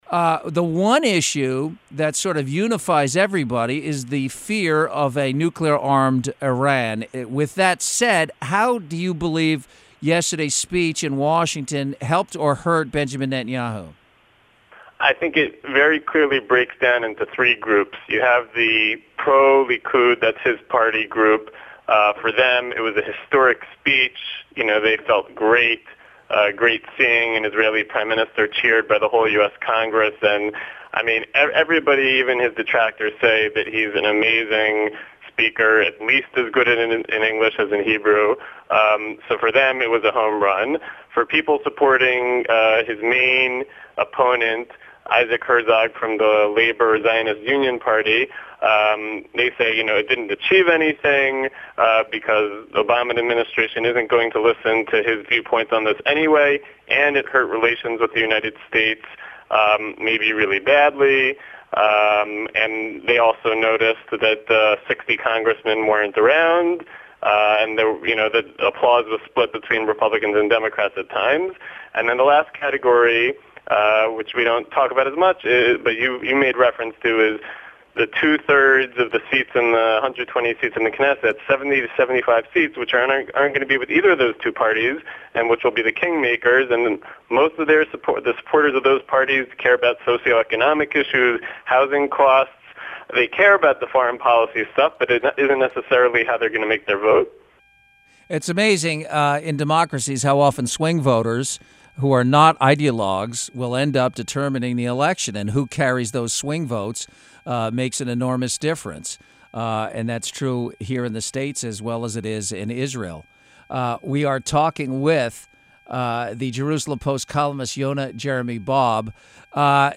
Interview with KABC Los Angeles Radio – US Iran Nuclear Deal 4/3/15